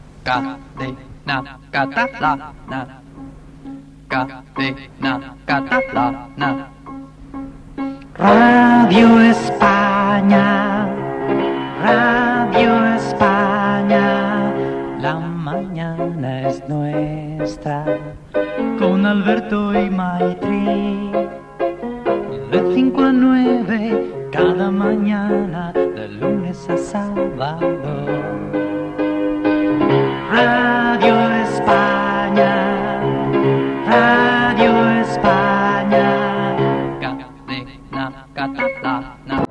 Indicatiu cantat de l'emissora i del programa.